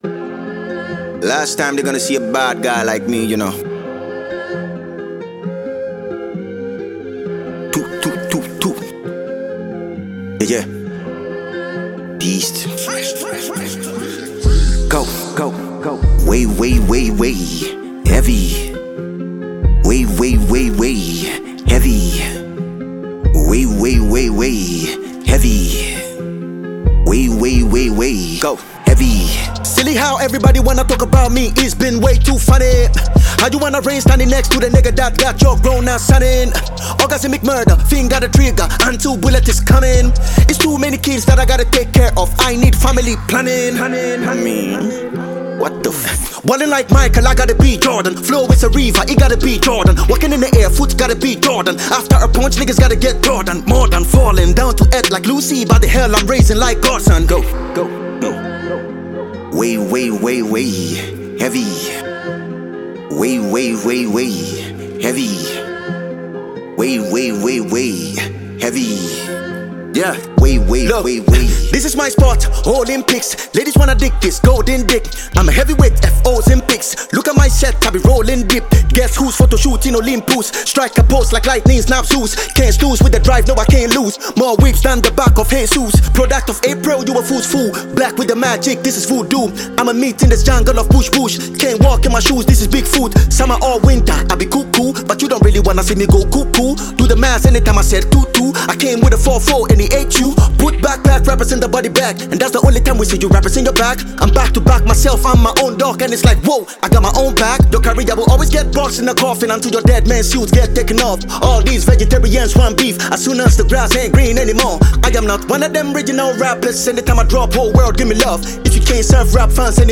Ghana Music
Enjoy new one from Ghanaian rapper